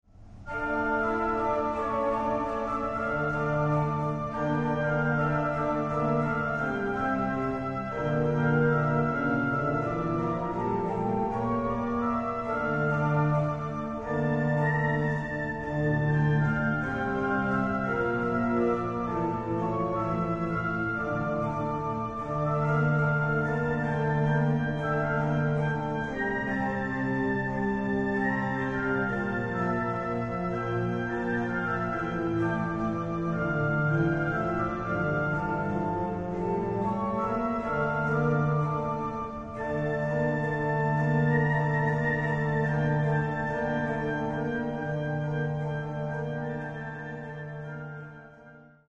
eseguite all'organo a canne
Organo costruito dai Fratelli Collino nel 1887 a Torino